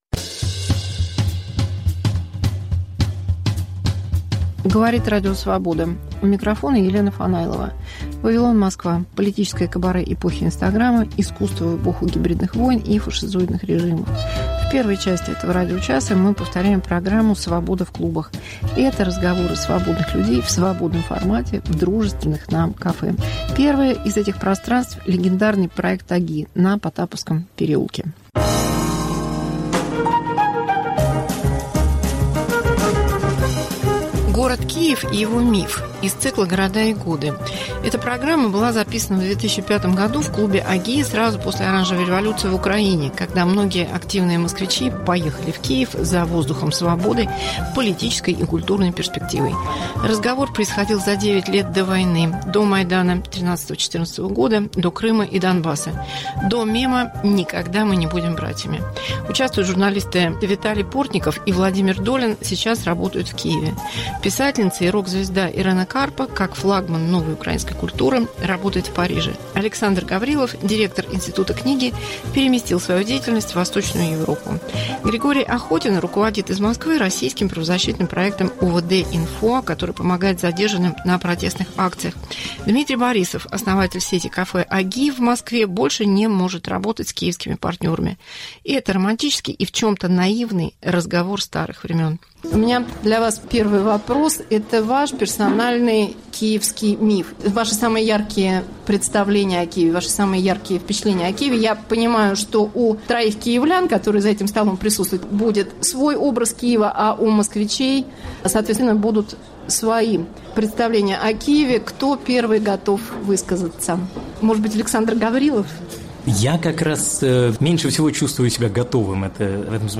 Мегаполис Москва как Радио Вавилон: современный звук, неожиданные сюжеты, разные голоса. 1. Архив "Свободы в клубах": миф о Киеве. 2.